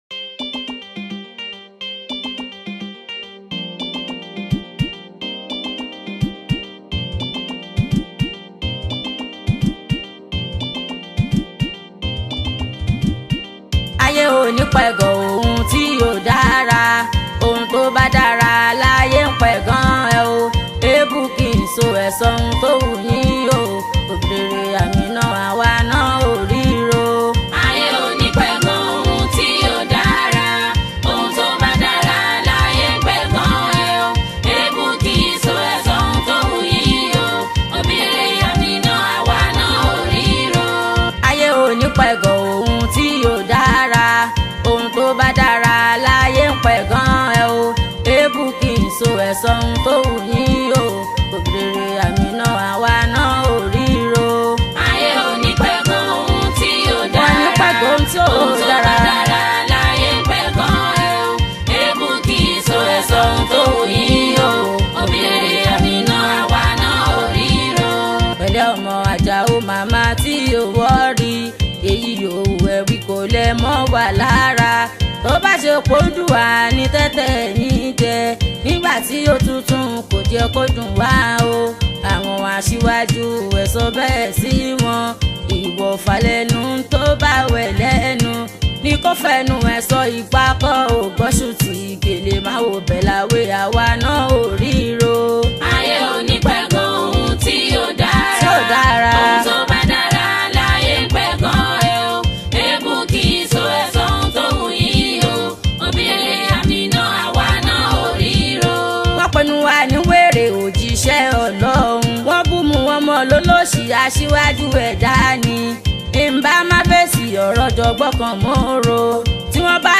Yoruba Islamic Music 0
Yoruba Fuji track